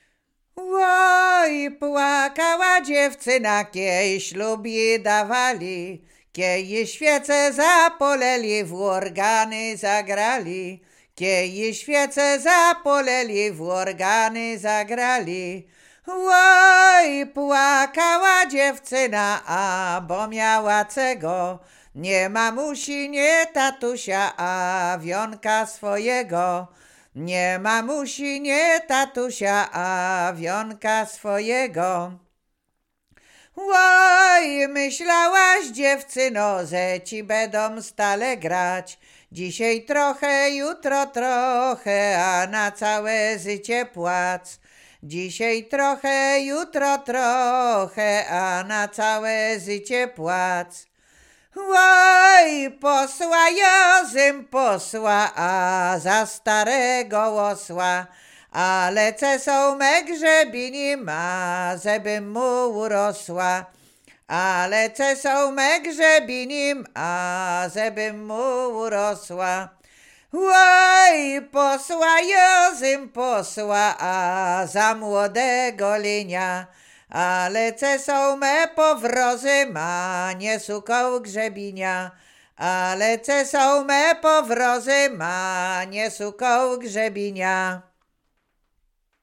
Łowickie
Przyśpiewki
miłosne przyśpiewki